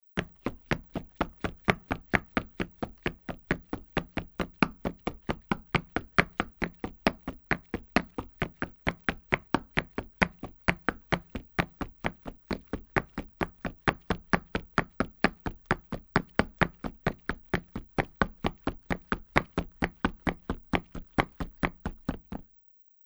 在较硬的路面上奔跑－YS070525.mp3
通用动作/01人物/01移动状态/01硬地面/在较硬的路面上奔跑－YS070525.mp3
• 声道 立體聲 (2ch)